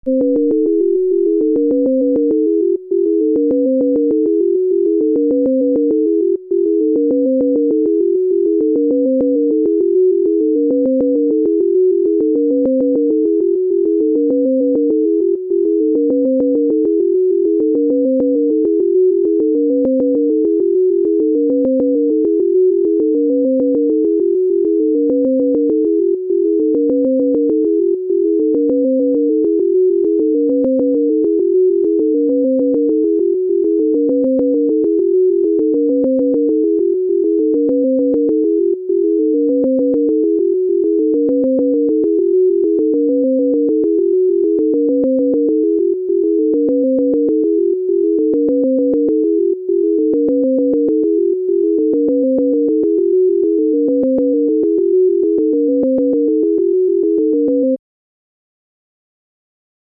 This is a stereo sound file
Most righthanders hear the higher tones on the right and the lower tones on the left, regardless of how the earphones are positioned.
Notice that when each channel is played separately, you hear a pattern that leaps around in pitch. Equalizing the balance causes your brain to reorganize the tones, so that you hear two smooth melodies instead.
Chromatic_Illusion_t10.mp3